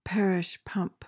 PRONUNCIATION:
(par-ish PUHMP)